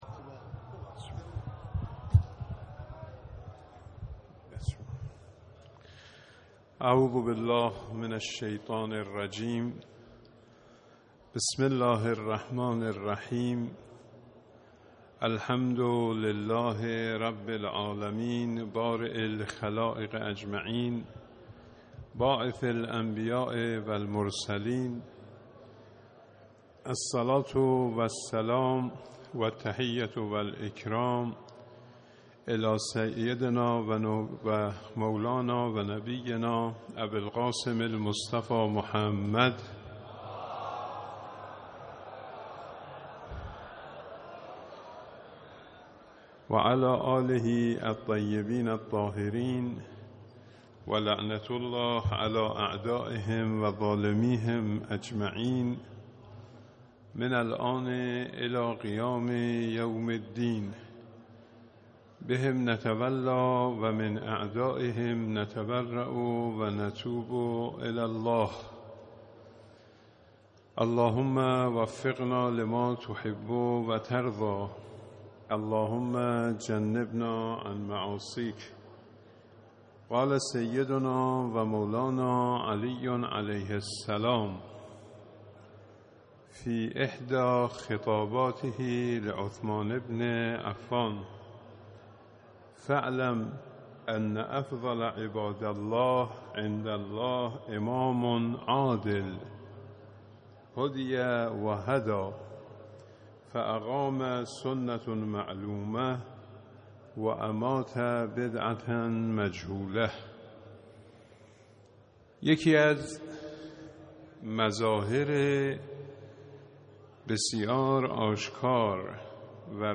سخنران